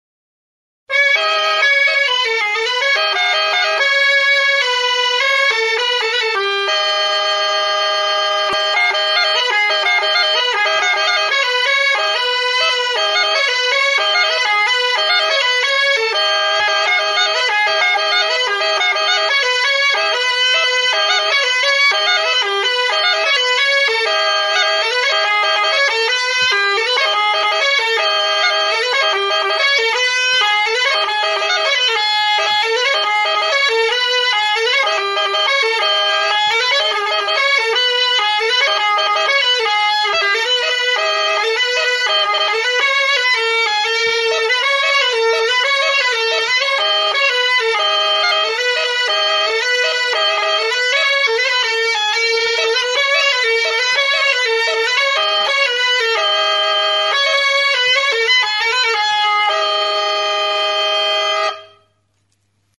Enregistr� avec cet instrument de musique.
ALBOKA